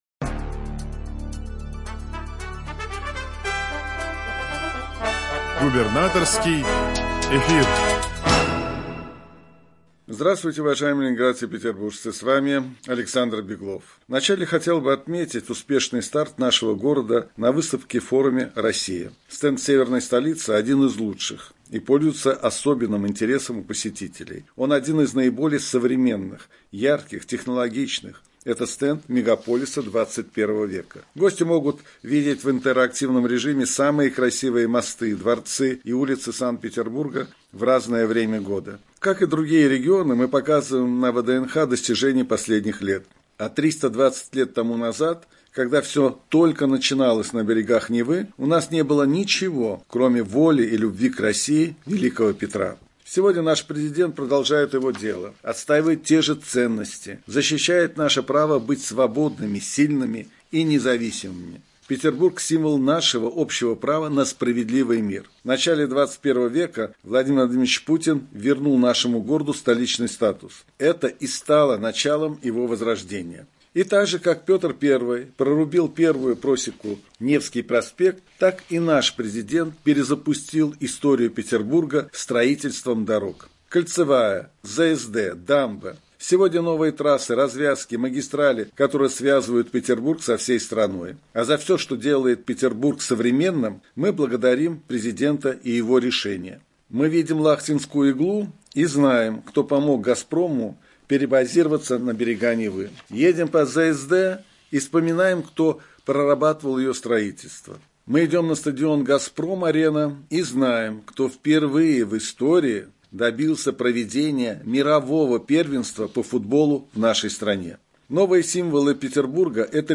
Радиообращение – 13 ноября 2023 года